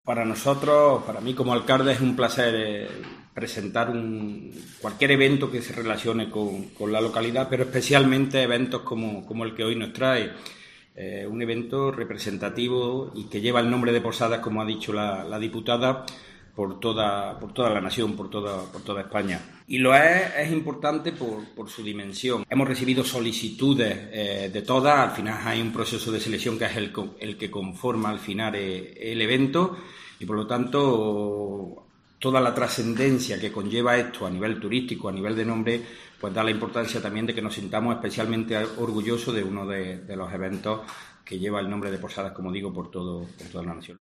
Escucha a Emilio Martínez, Alcalde de Posadas